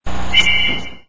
（スマートフォン）   ホイッスル－１　1秒
whistle1.wma